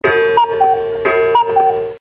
Coo Coo Clock - Часы с кукушкой